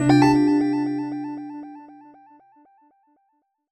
jingle_chime_01_positive.wav